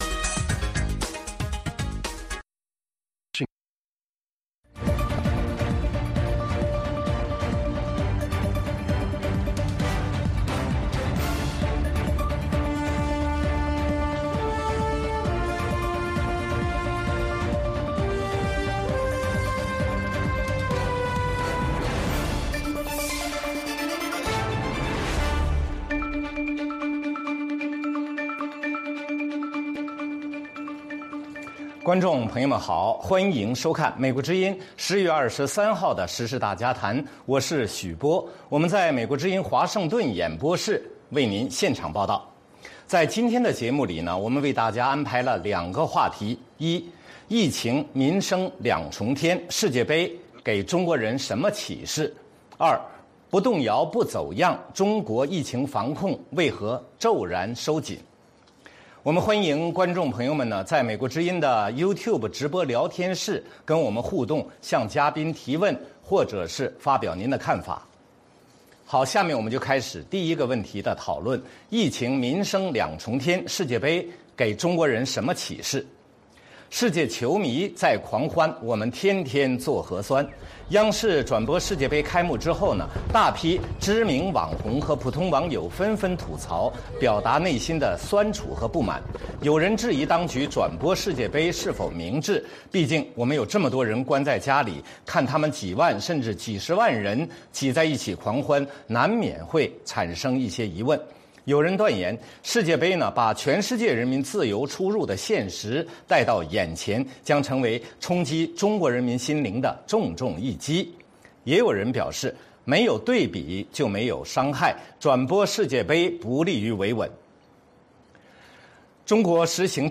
美国之音中文广播于北京时间每周一到周五晚上9点到10点播出《时事大家谈》节目(电视、广播同步播出)。